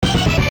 jingles-hit_01.ogg